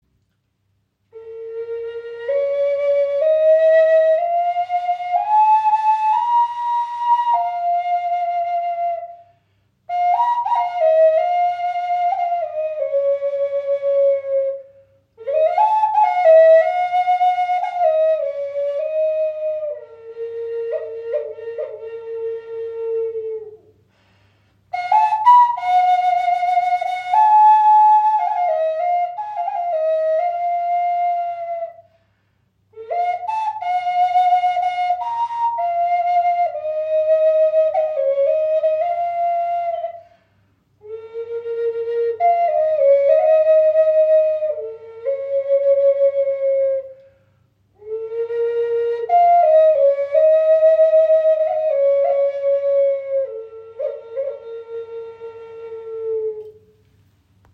Okarina aus Wurzelstück | B / H in 432 Hz | Pentatonische Stimmung | ca. 21 cm
Klein, handlich, klangvoll – eine Okarina mit Seele
In pentatonischer B / H Moll Stimmung auf 432 Hz gestimmt, entfaltet sie einen warmen, klaren Klang, der Herz und Seele berührt.
Trotz ihrer handlichen Grösse erzeugt sie einen angenehm tiefen und warmen Klang – fast ebenbürtig zur nordamerikanischen Gebetsflöte.